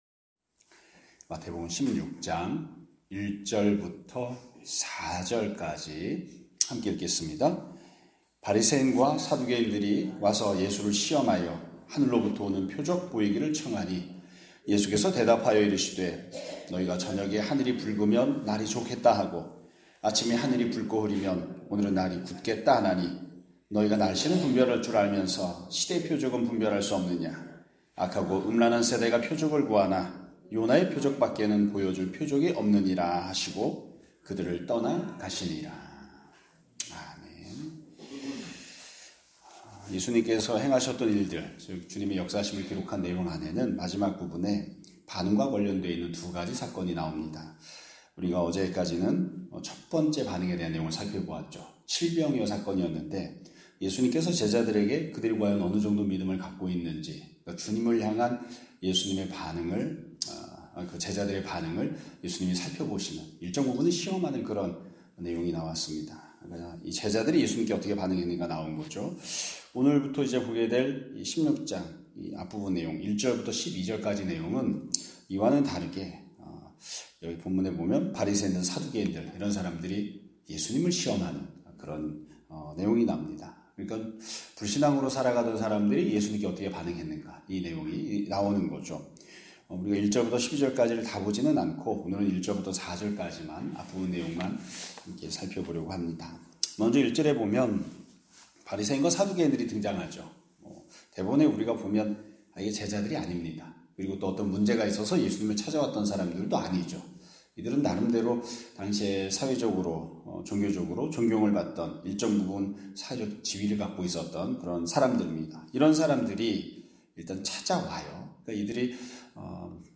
2025년 11월 13일 (목요일) <아침예배> 설교입니다.